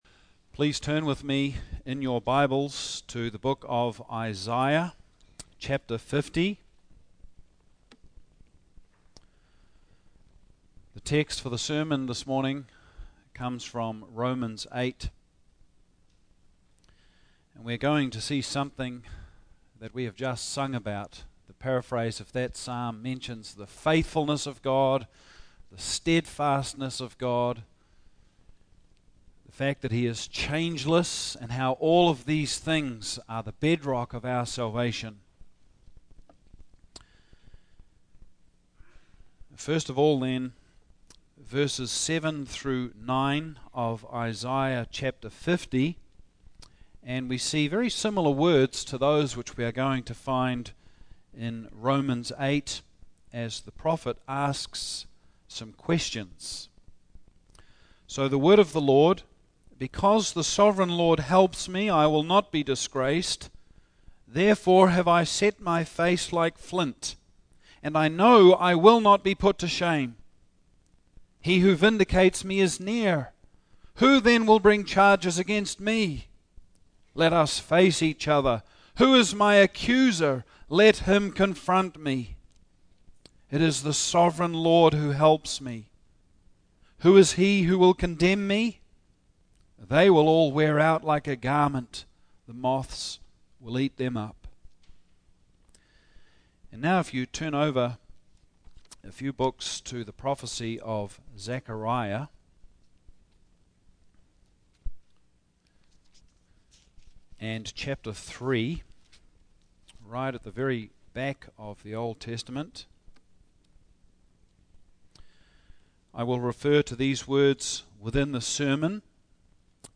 Single Sermons